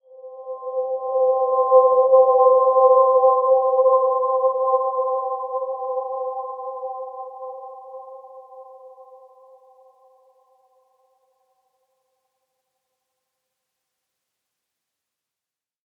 Dreamy-Fifths-C5-f.wav